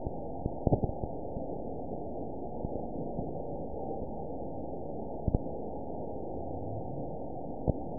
event 922859 date 04/22/25 time 20:17:54 GMT (1 month, 3 weeks ago) score 8.88 location TSS-AB01 detected by nrw target species NRW annotations +NRW Spectrogram: Frequency (kHz) vs. Time (s) audio not available .wav